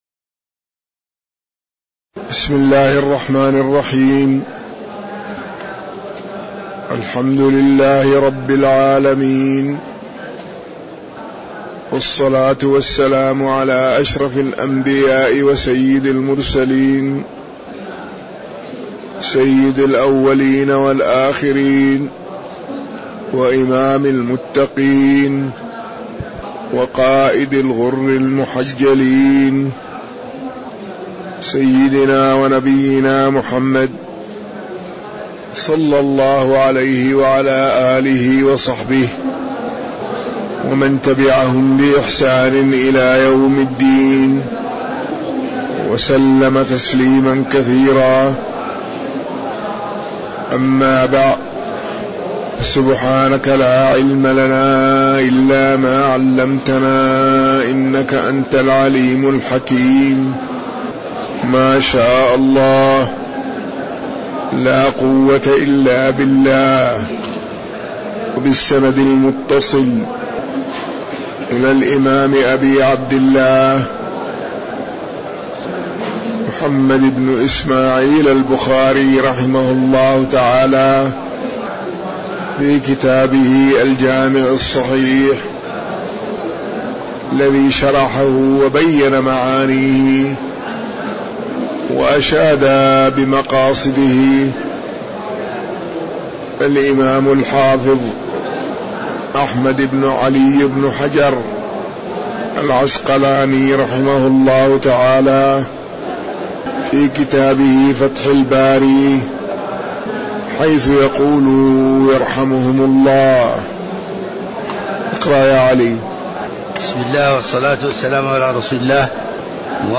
تاريخ النشر ٢٠ ذو الحجة ١٤٤٠ هـ المكان: المسجد النبوي الشيخ